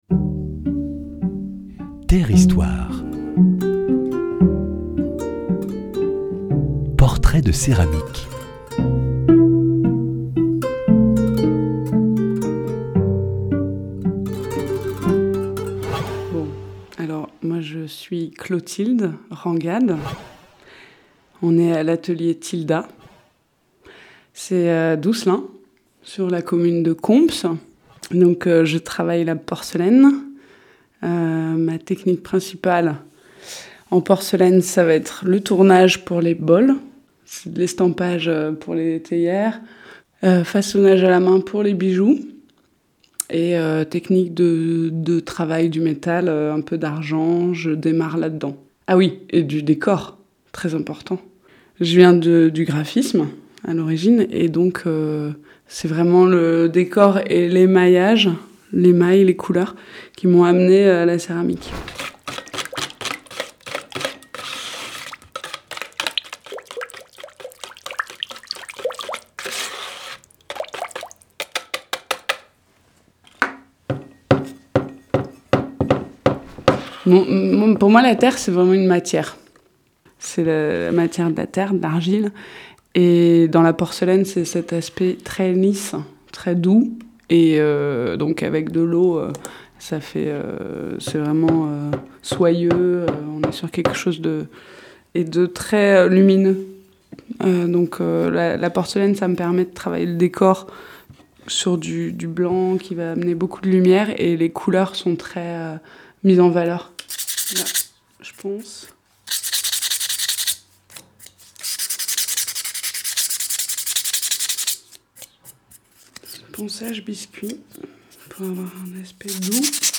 Dans le cadre du 14e marché des potiers de Dieulefit, portraits de céramiques en atelier: matériaux, gestes créatifs, lien avec la matière, 8 potiers évoquent la terre de leur quotidien….